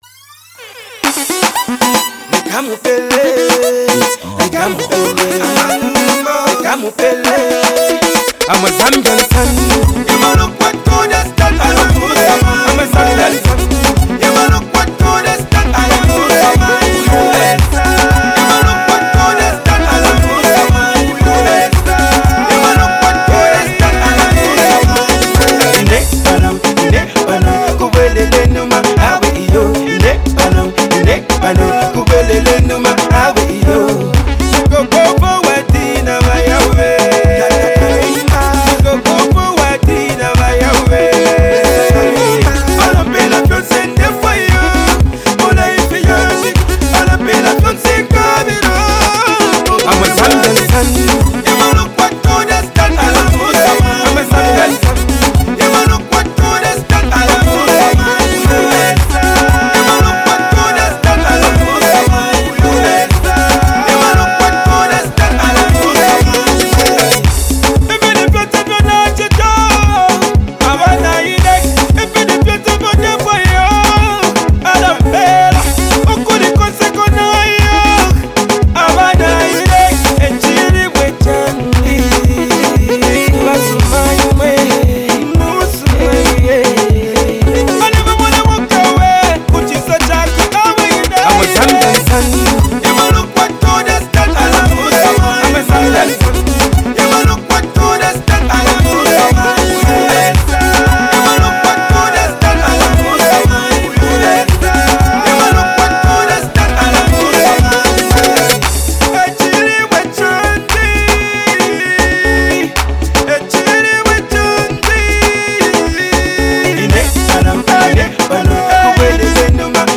a vibrant mix of cultural pride and catchy rhythms
With its powerful lyrics and infectious energy